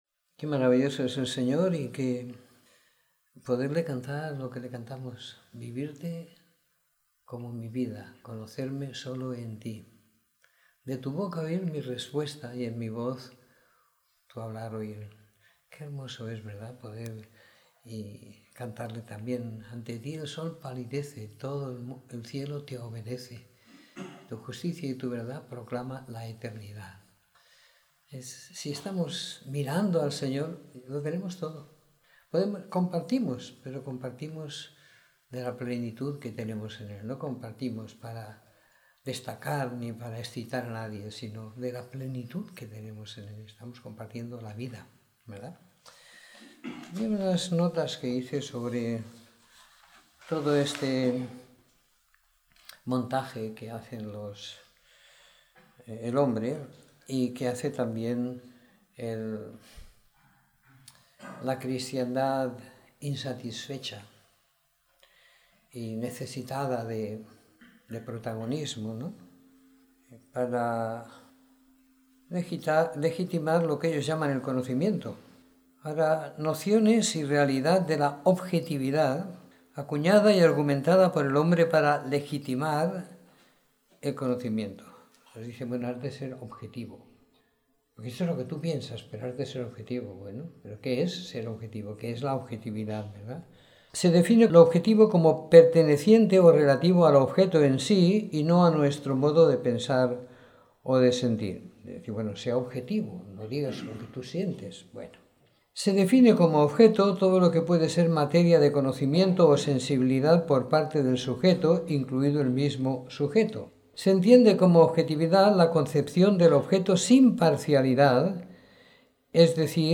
Domingo por la Tarde . 15 de Enero de 2017